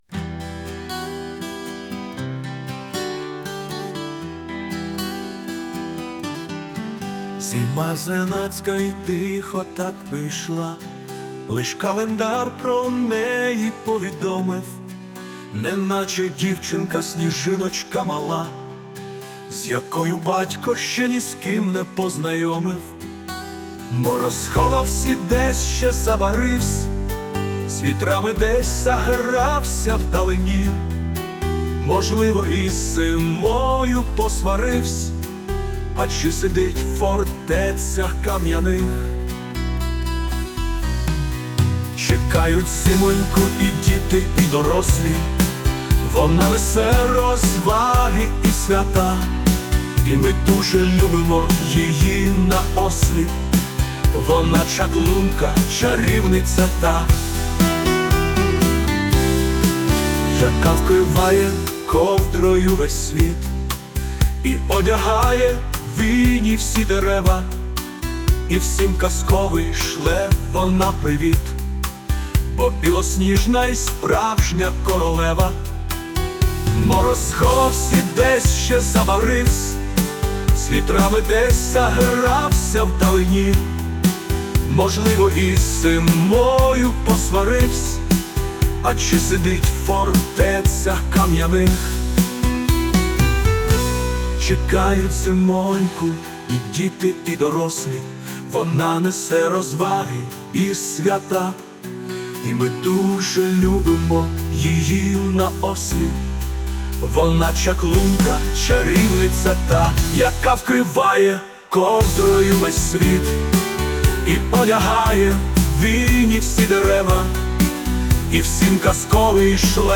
Музика і співак згенеровані
ШІ ( Штучним Інтелектом )
(дитячий  контент )